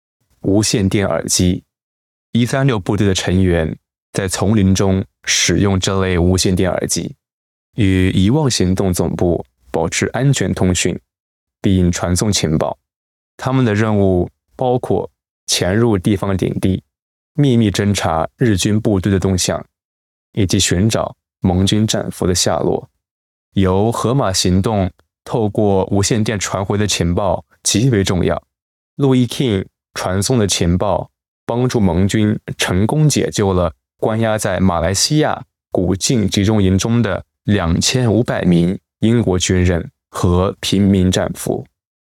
Special Operations Voiceovers
3_MAND_Radio_Headset_voiceover__eq_.mp3